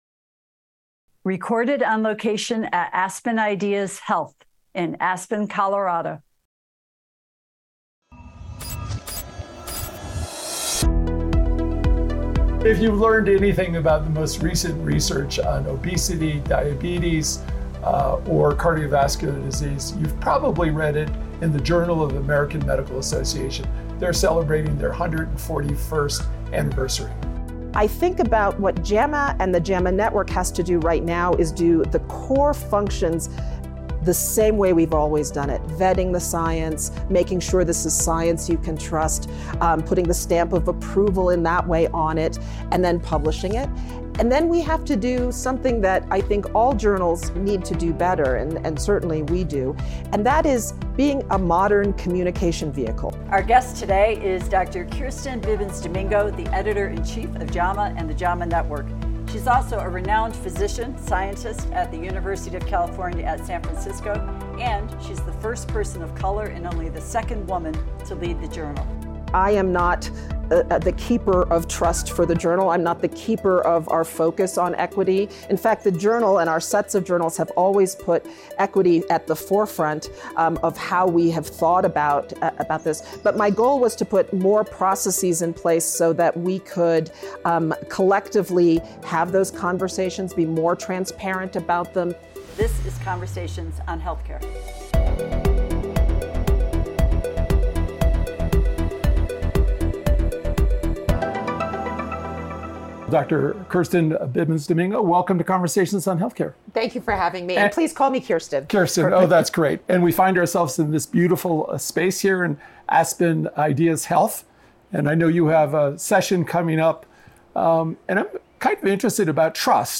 at Aspen Ideas: Health